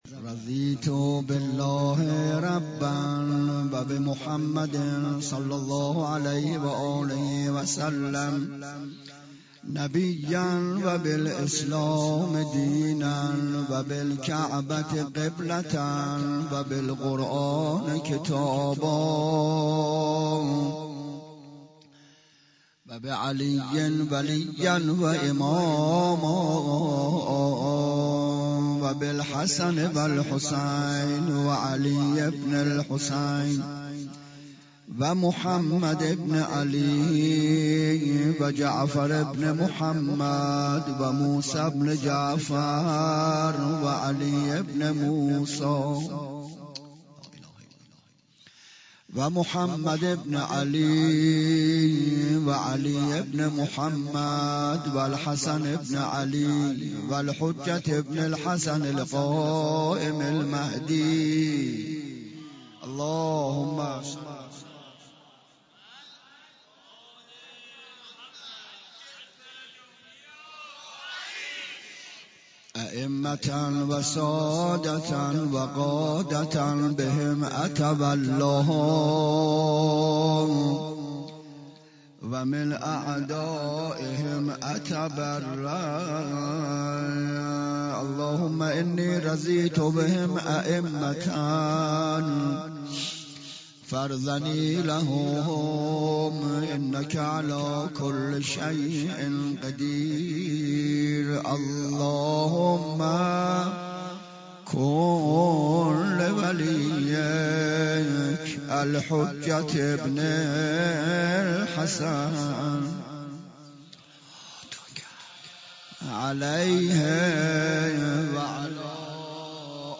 19 محرم 97 - حسینیه کربلایی های یزد - سخنرانی